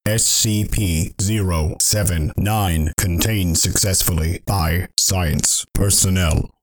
New 079 death sounds for 11.0